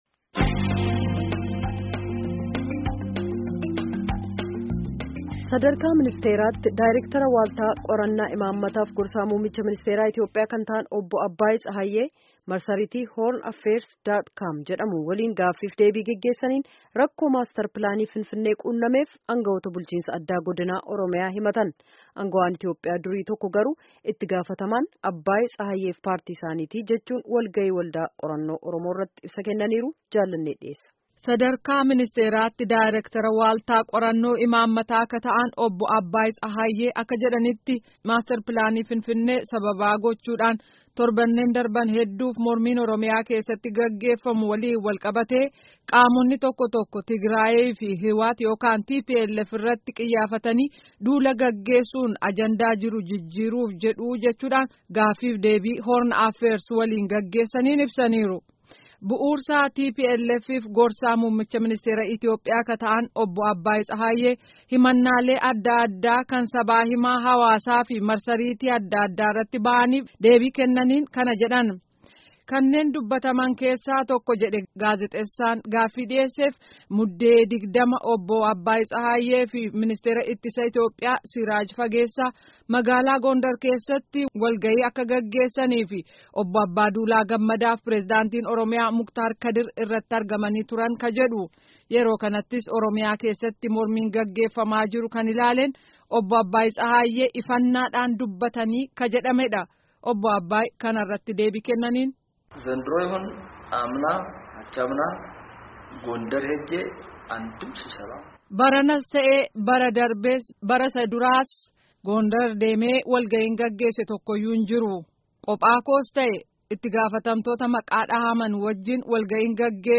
Gutummaa gabaasa kanaa armaa gadiitti dhaggeffadhaa